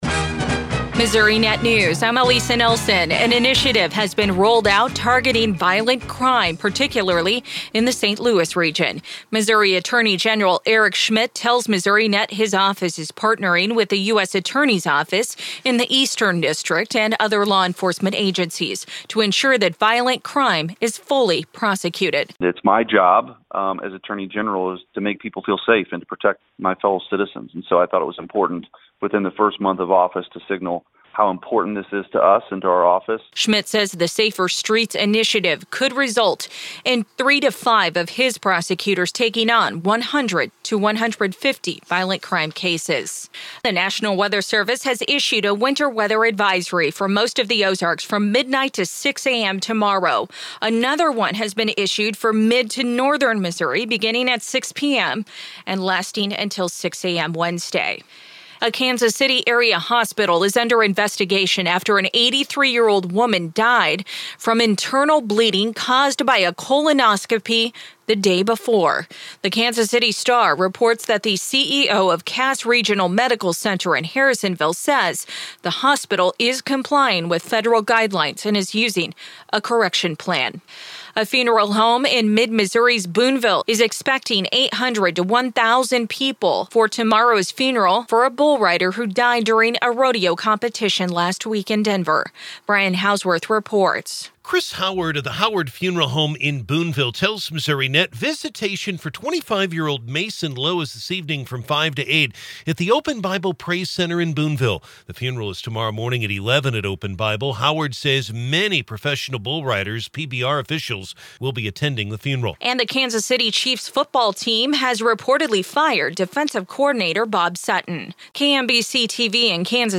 Missourinet-5-p.m.-Newscast.mp3